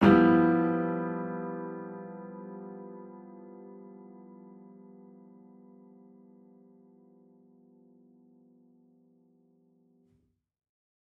Index of /musicradar/gangster-sting-samples/Chord Hits/Piano
GS_PiChrd-G6min7.wav